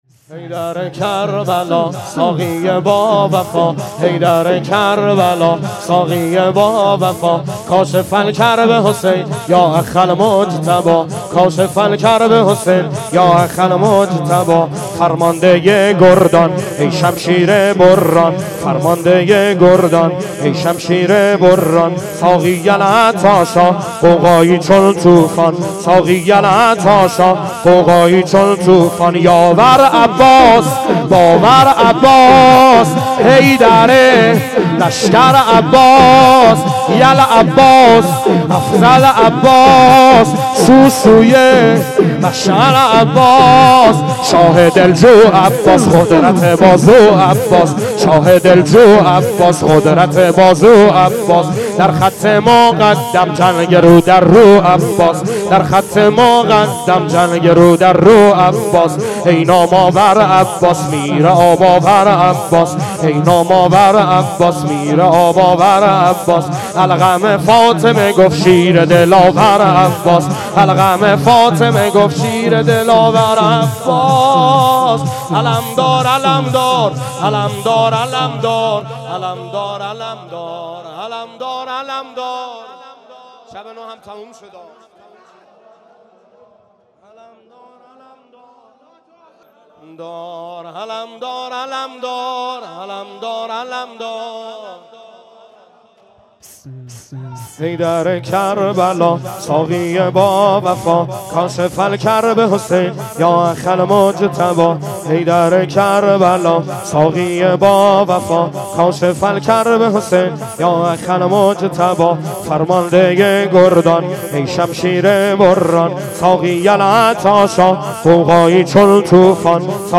شور | حیدر کربلا ساقی باوفا
گزارش صوتی شب نهم (تاسوعا) محرم 96 | هیأت محبان حضرت زهرا سلام الله علیها زاهدان